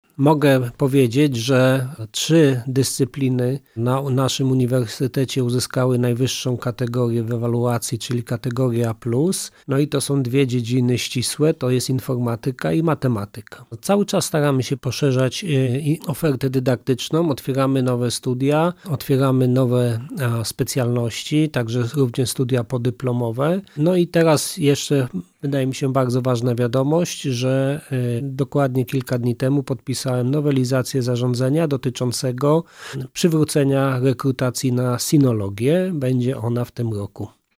Wynik komentuje rektor prof. Robert Olkiewicz,  rektor Uniwersytetu Wrocławskiego.